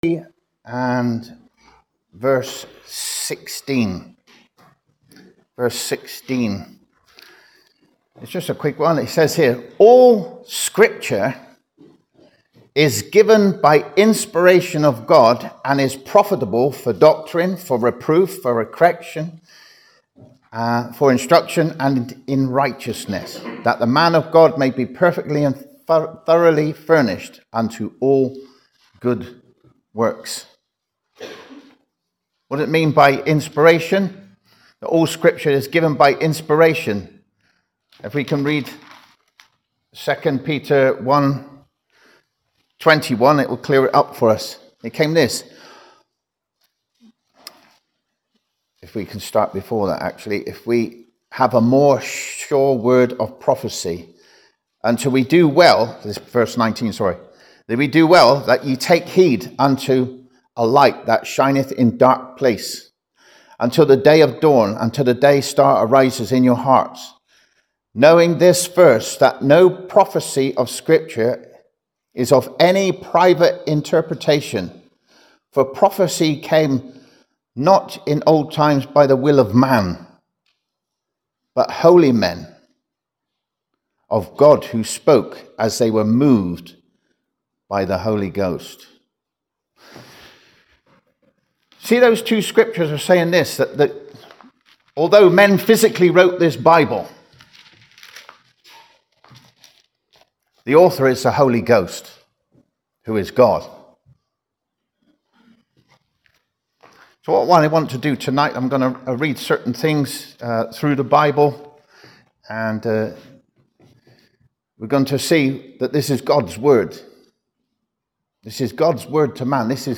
discusses the inerrancy of the Bible in our gospel meeting. He proves that the Bible is prophetically true, archaeologically provable, scientifically testable historically accurate and personally verifiable.